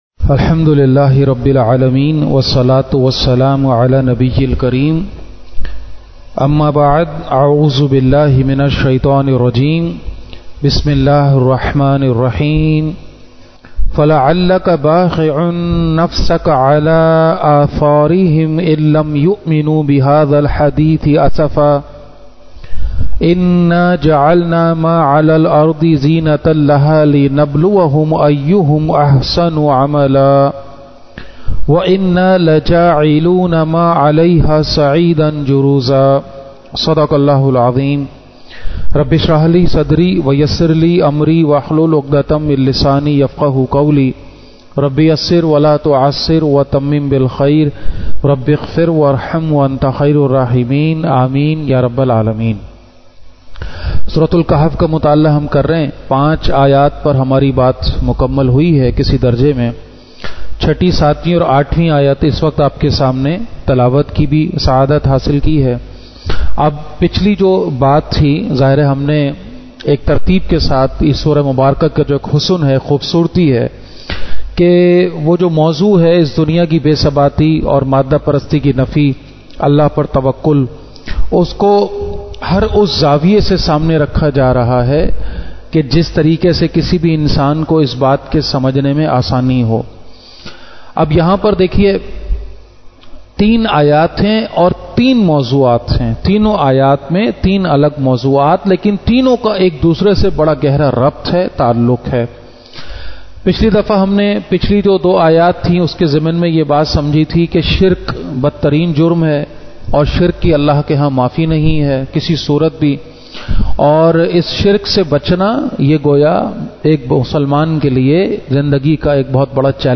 Khutbat-e-Jummah (Friday Sermons)
at Masjid Jame Al-Quran, Quran Institute Johar. Study of Surah Al-Kahf Part 5 | مطالعہ سورہ کہف حصہ پنجم۔